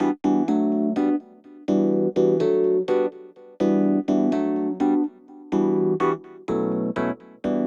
32 ElPiano PT1.wav